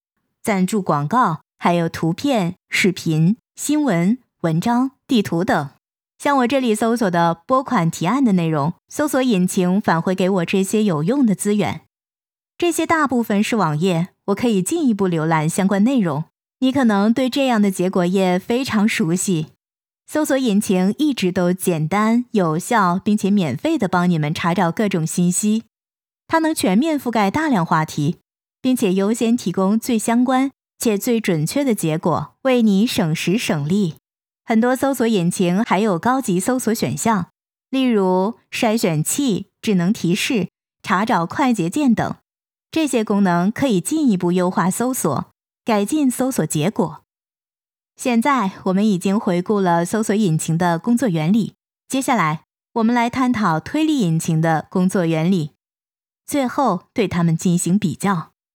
Chinese_Female_045VoiceArtist_2Hours_High_Quality_Voice_Dataset
Text-to-Speech